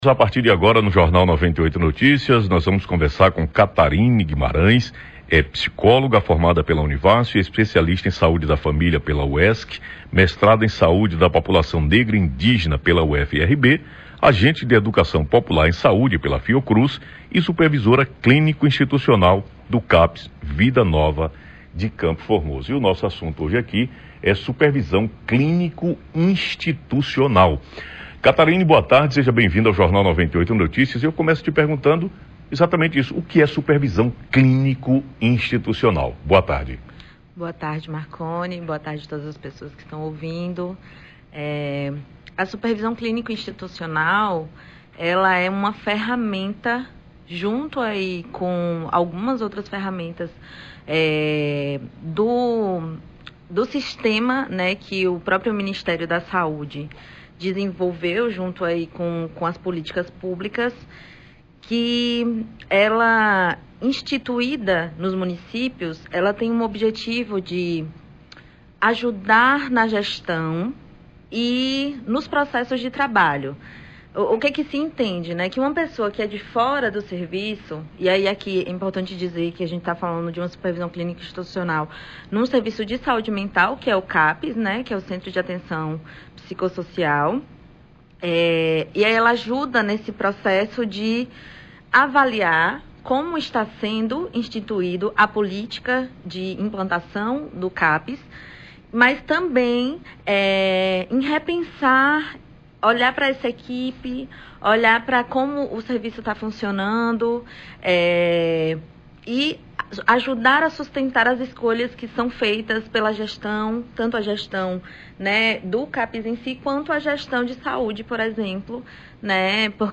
entrevista-com-a-psicologa.mp3